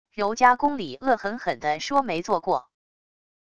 柔嘉宫里恶狠狠的说没做过wav音频